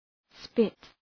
Προφορά
{spıt}